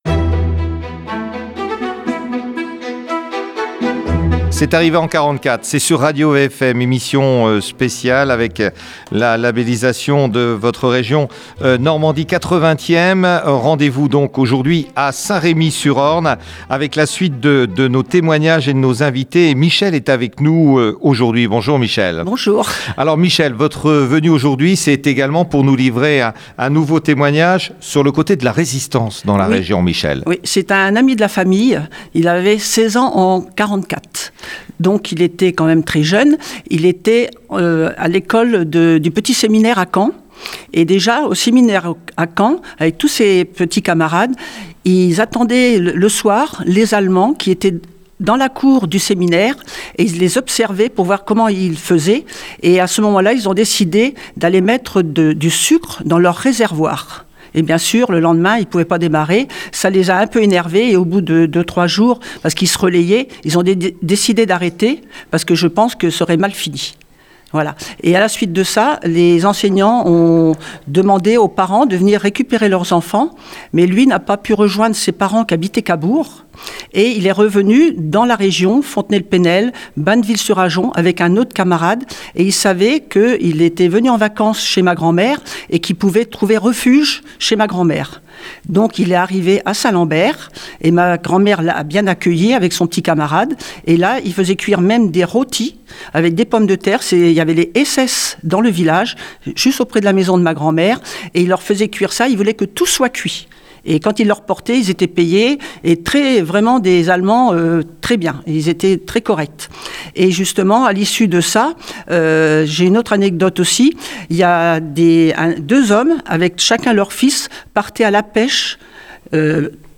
Émission exceptionnelle diffusée Dimanche 16 Juin à Saint-Remy sur Orne à 10 h 15. Témoignages, récits de la libération du Bocage.